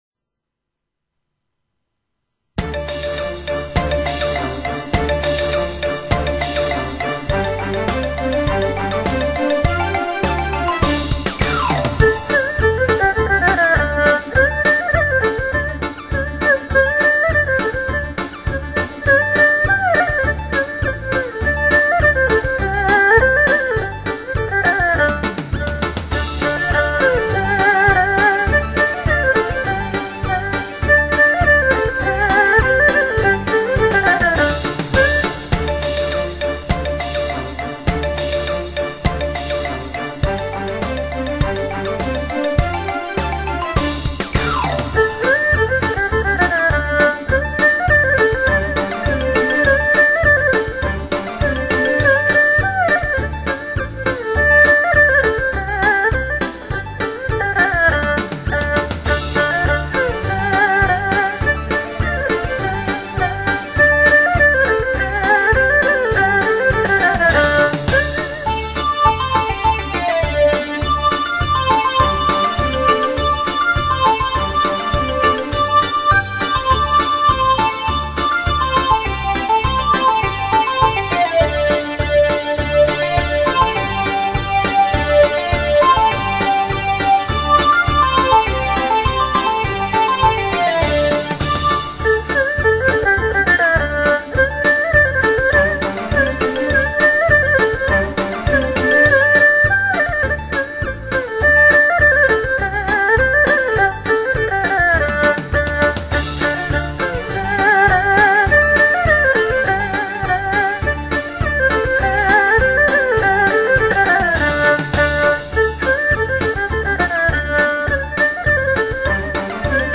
二胡演奏